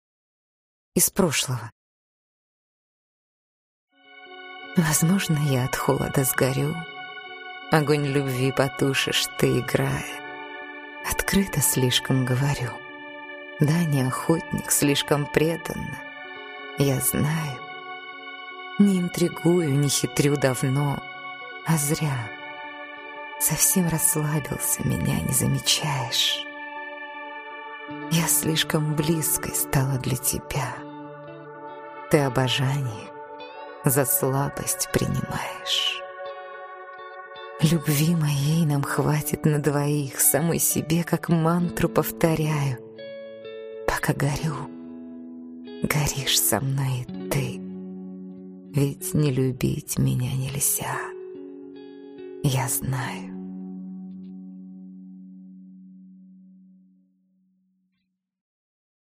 Аудиокнига Возможно я от холода сгорю… Лирика | Библиотека аудиокниг